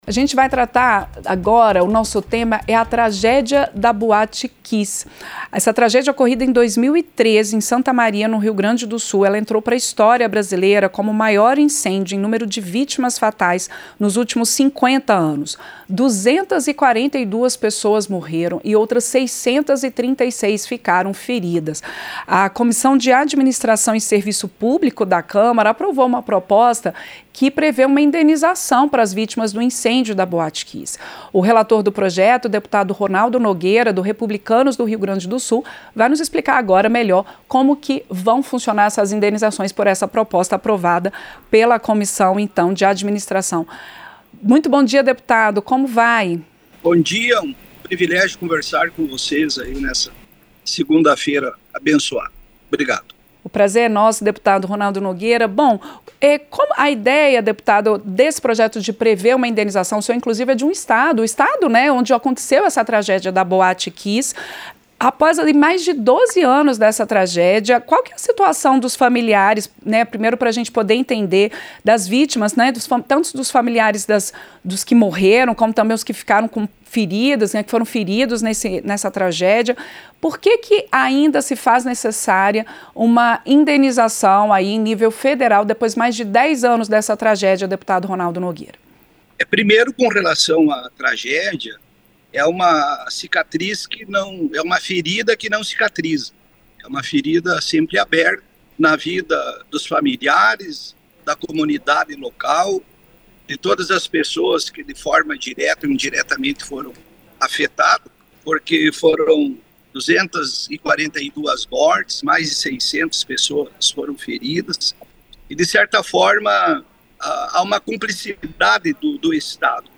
• Entrevista - Dep. Ronaldo Nogueira (Republicanos-RS)
Em entrevista ao Painel Eletrônico (9/6), o relator da proposta, deputado [[Ronaldo Nogueira]] (Republicanos-RS), defendeu a indenização.
Programa ao vivo com reportagens, entrevistas sobre temas relacionados à Câmara dos Deputados, e o que vai ser destaque durante a semana.